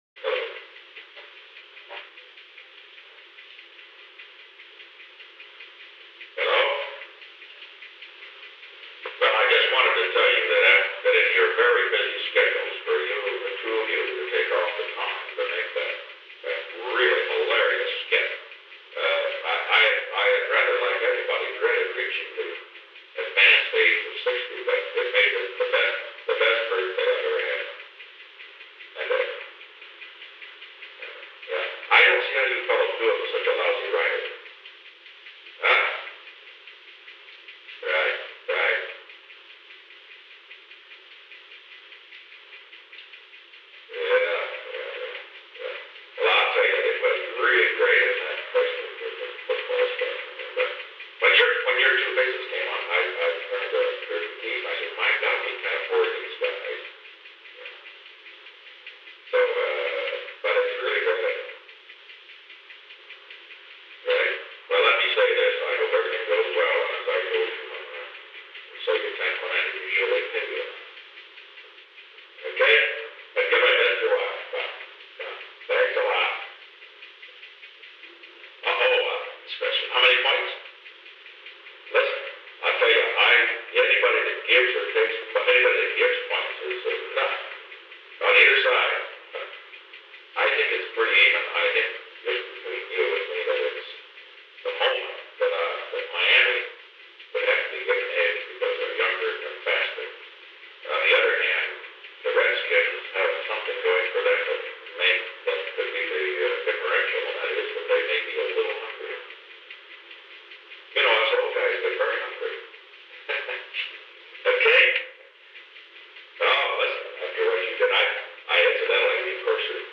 Conversation: 395-011
The Old Executive Office Building taping system captured this recording, which is known as Conversation 395-011 of the White House Tapes.
The President talked with Dan Rowan and Dick Martin.